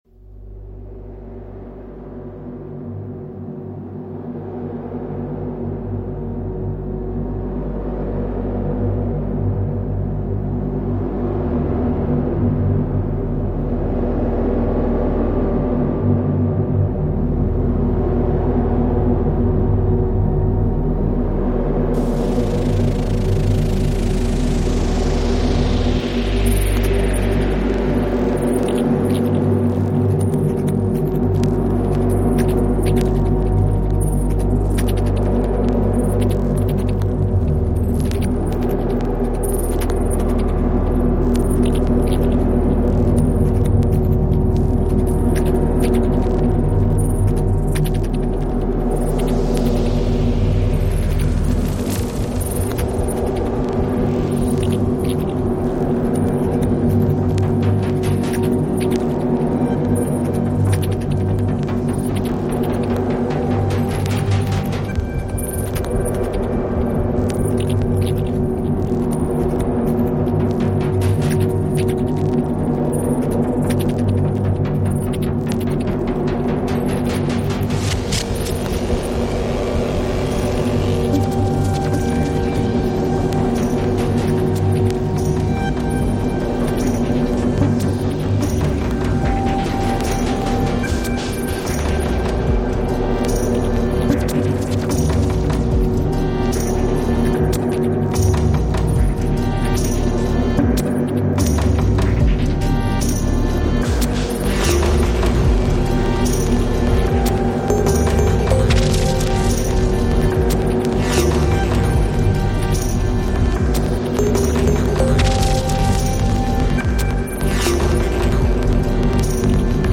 Indie electronic musician/producer